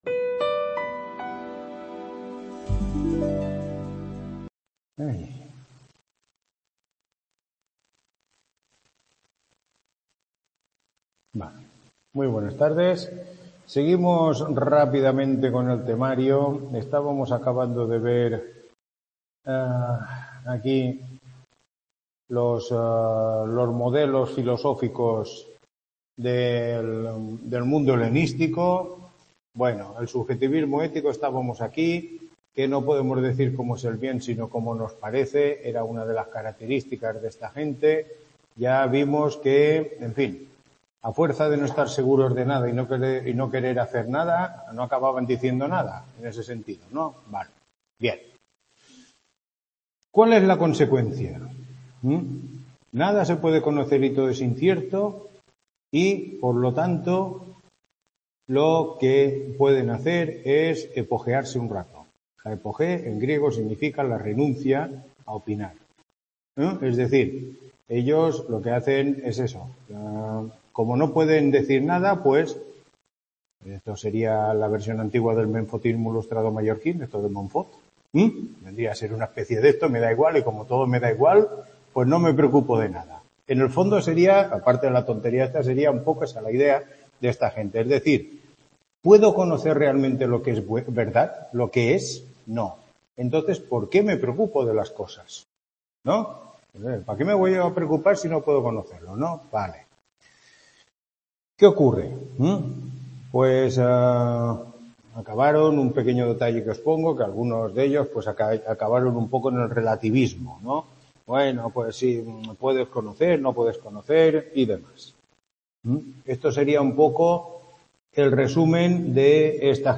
Tutoría 7